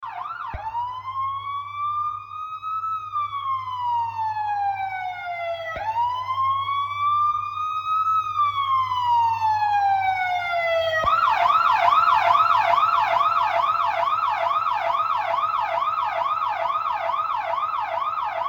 Download Police Station sound effect for free.
Police Station